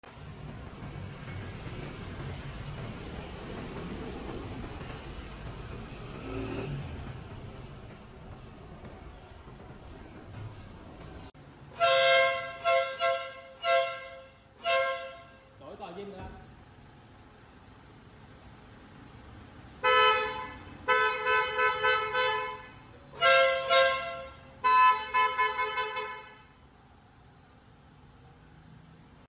Ai muốn bạo lực thì còi lốc/ còi ong cứ chơi nha Mình chơi nhẹ nhàng, k gây chú ý thì lắp (còi Ý) còi hơi điện Marco nhanh, gọn mà đẹp nha